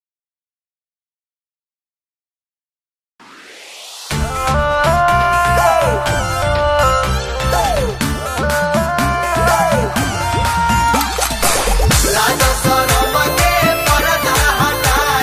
Bhojpuri Song